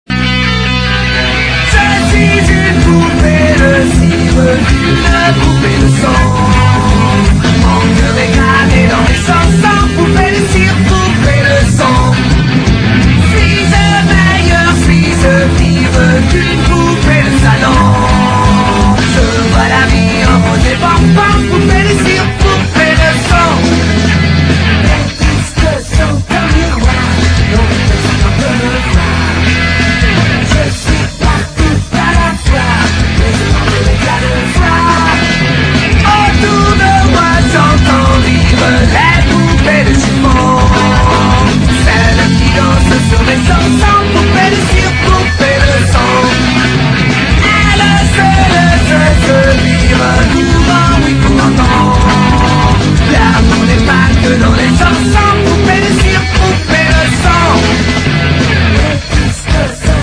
ROCK / PUNK / 80'S～ / Oi! / STREET PUNK (UK)
骨太なOi!/ストリート・パンク名曲4曲を収録したボーナス7インチ付き！